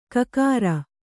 ♪ kakāra